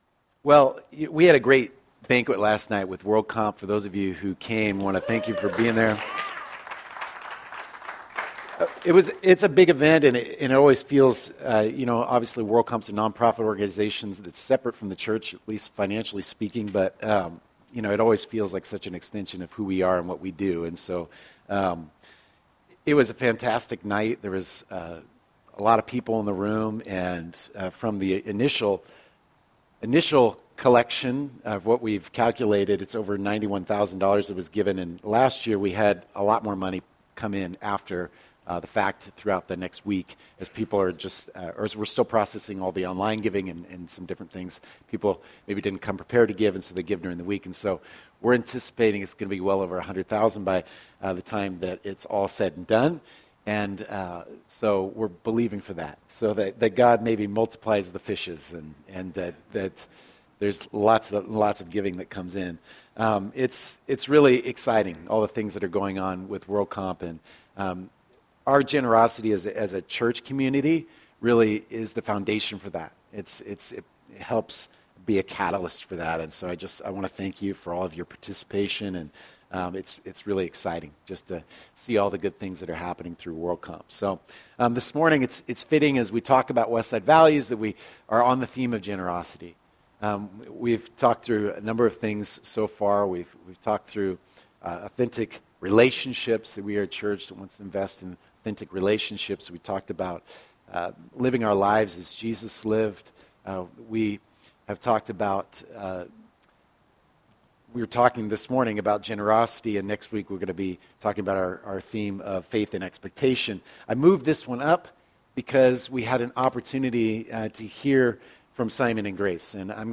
A few years ago, we set out to put words to those passions and we compiled a list of our core values. During this sermon series, we will be sharing them with you!